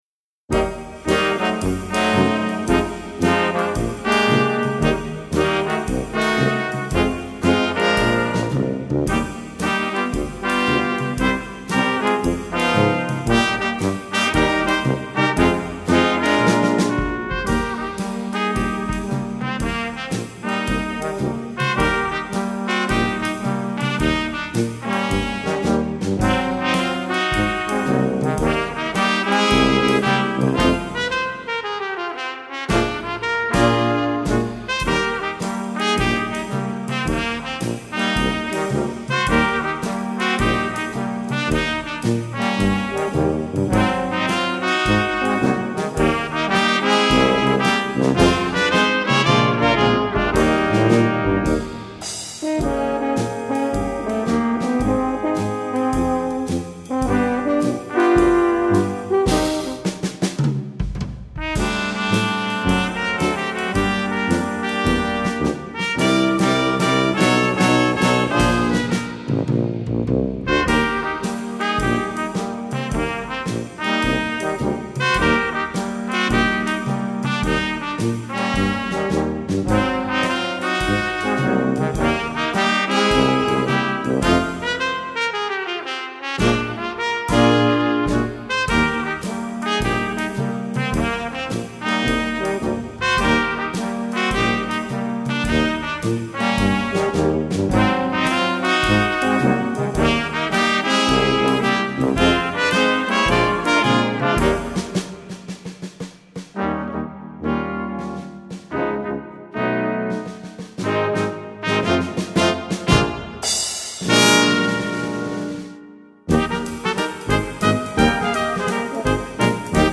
Gattung: für gemischtes Bläserquintett
Besetzung: Ensemble gemischt
Piano, Keyboard (optional)